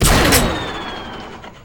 ogg / general / combat / enemy / pshoot2.ogg
pshoot2.ogg